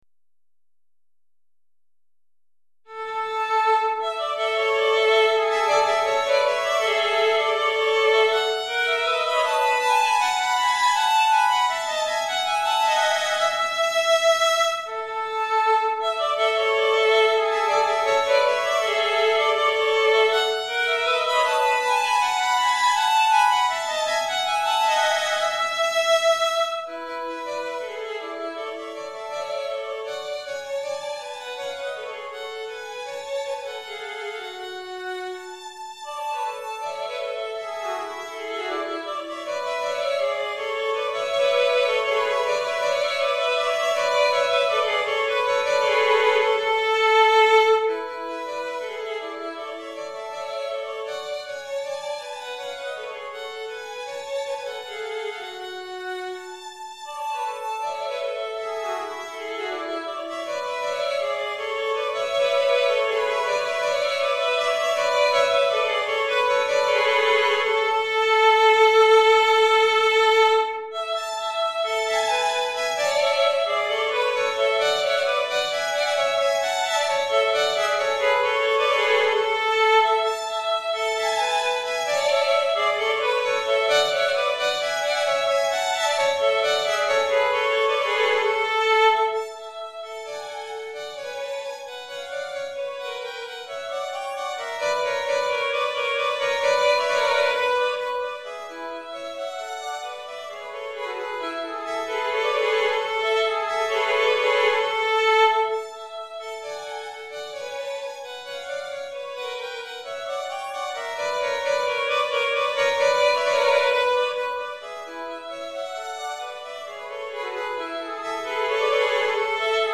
Répertoire pour Violon - 2 Violons